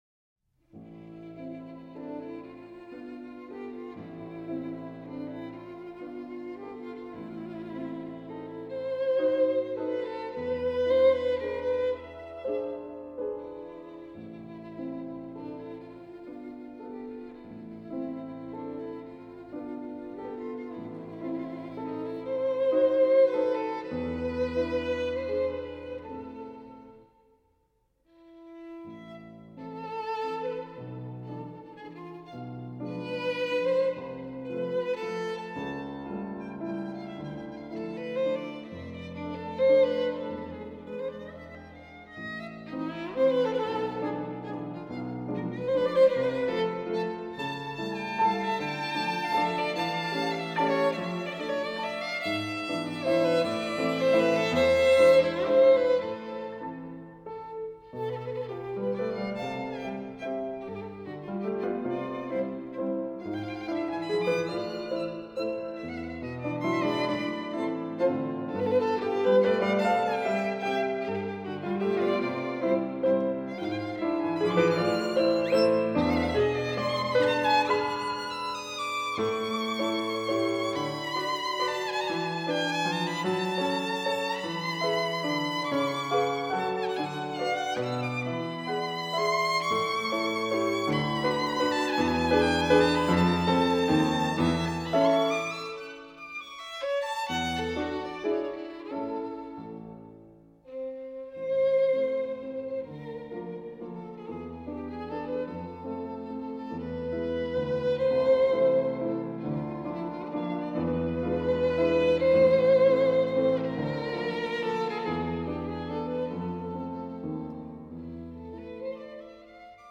12 Waltz Op.34-2 (arr. Sarasate)
12-waltz-op-34-2-arr-sarasate.m4a